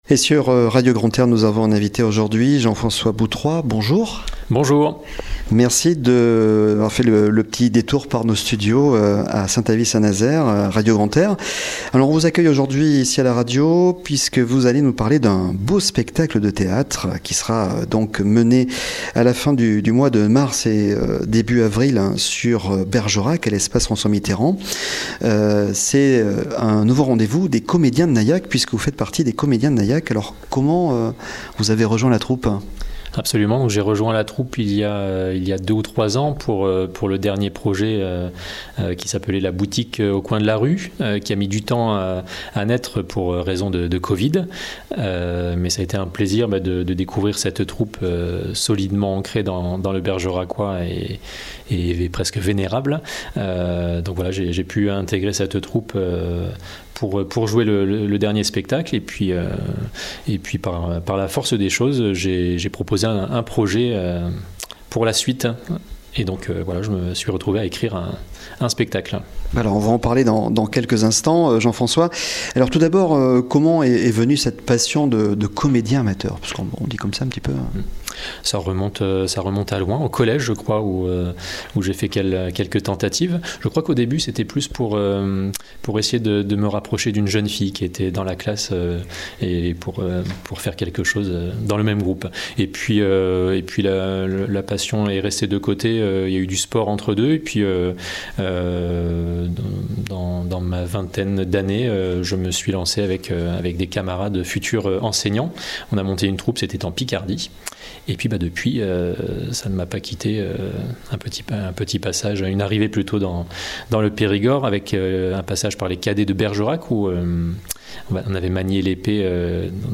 Le podcast des invités de Radio Grand "R" !